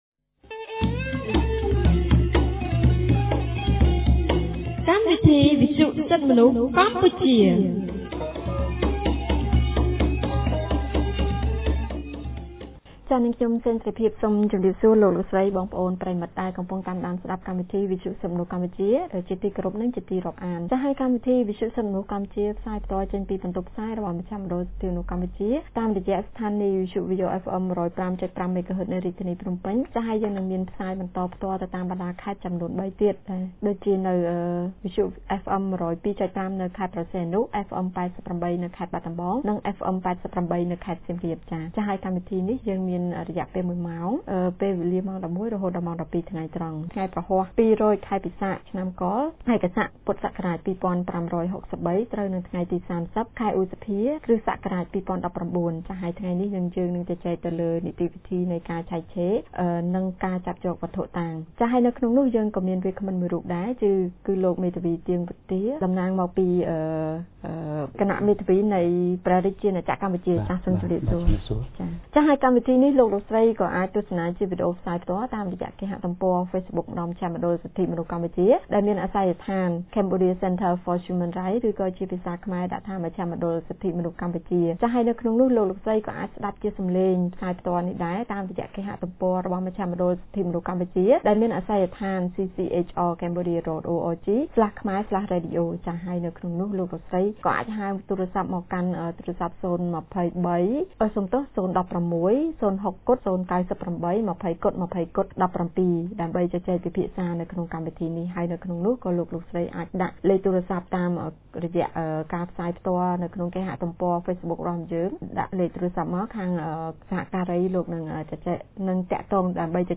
On 30 May 2019, CCHR's Fair Trial Rights Project (FTRP) held a radio program with a topic on Procedure of Searches and Seizures.